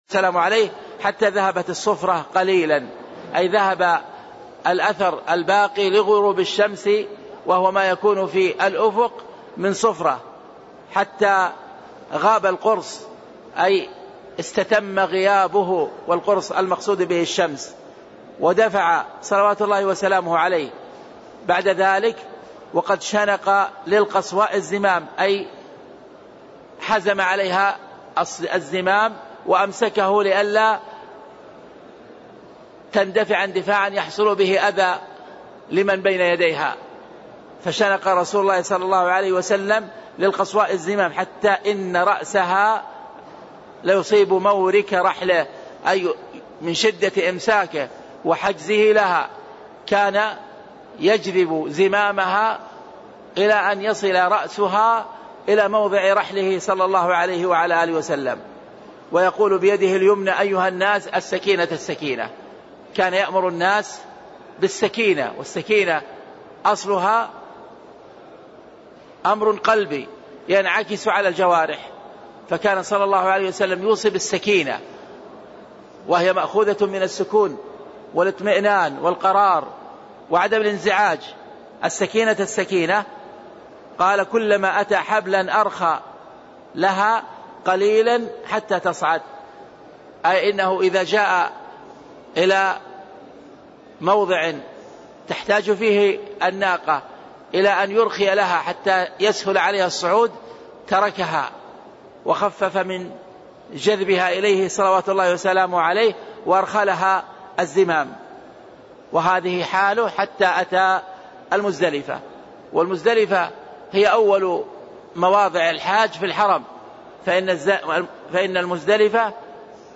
تاريخ النشر ١١ ذو القعدة ١٤٣٦ هـ المكان: المسجد النبوي الشيخ